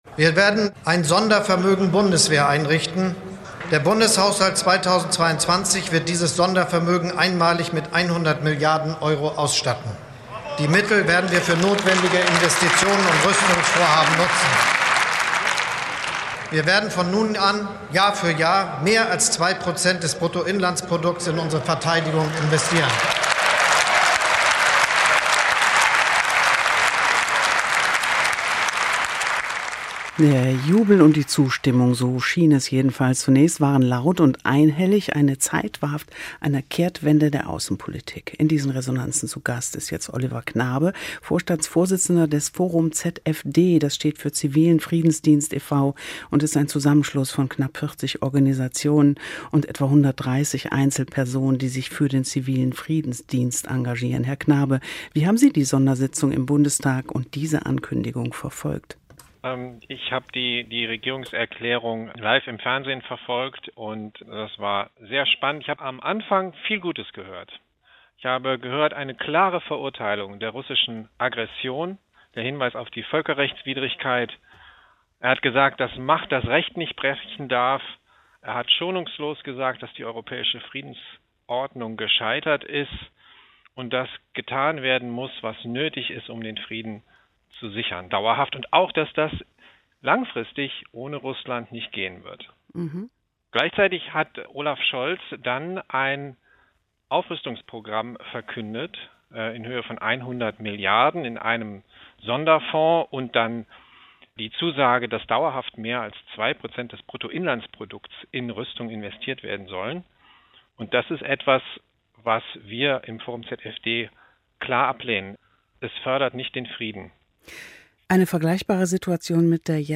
Hier kommen Sie zum Interview bei WDR 3 - Resonanzen.